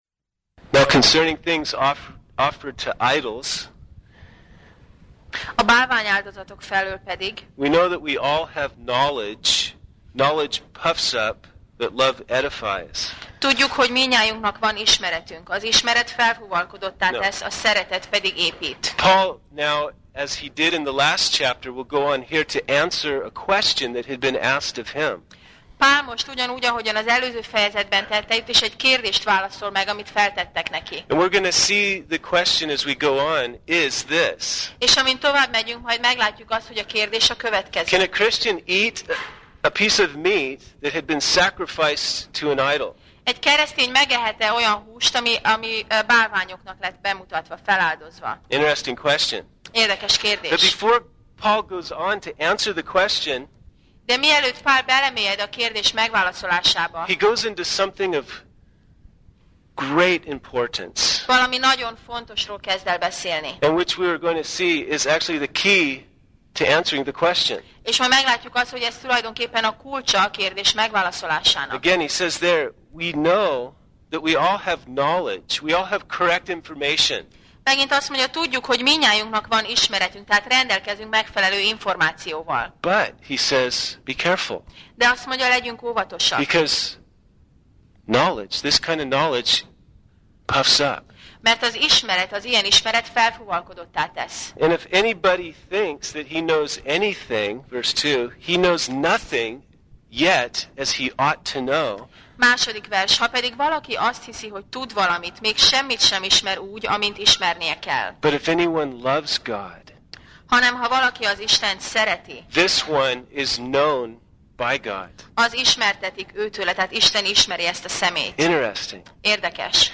1Korinthus Passage: 1Korinthus (1Cor) 8 Alkalom: Vasárnap Reggel